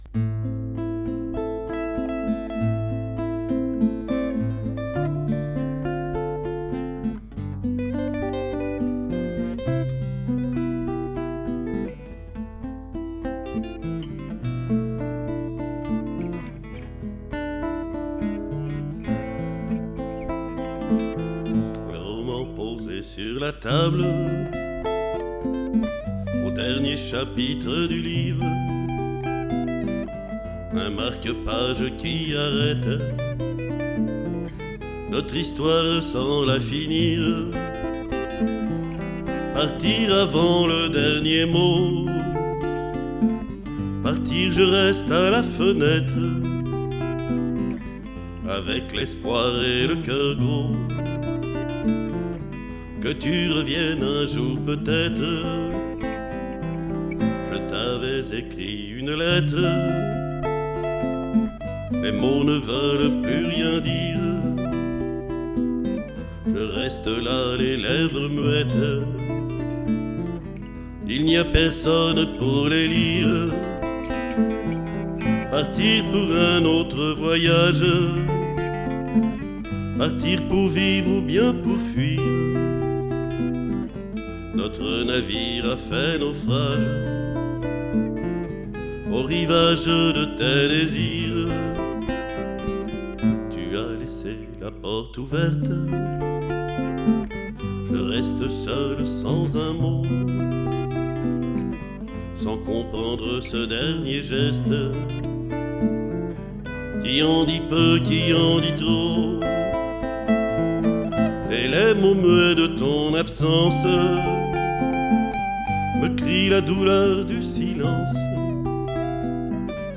Guitare solo